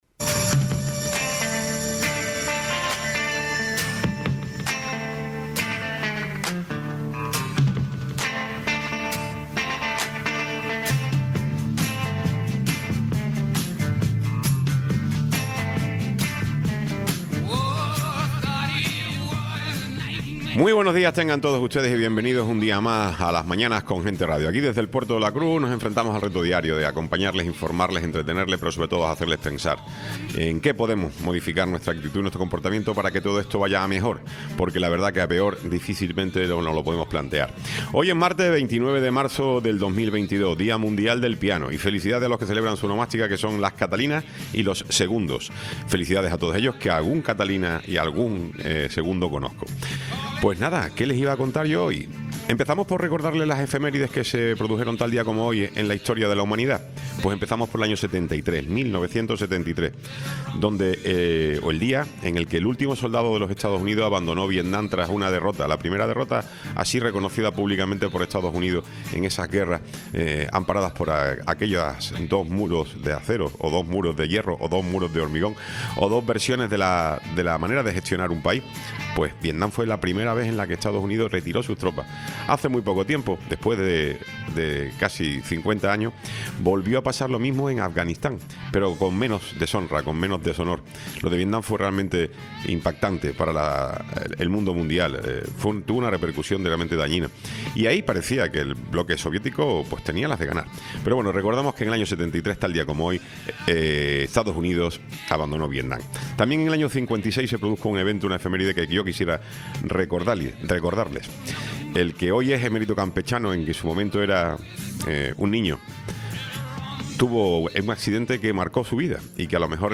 Tiempo de entrevista con Julia Navas y Carolina Rodríguez, concejalas del grupo de gobierno en el Ayto. de Puerto de la Cruz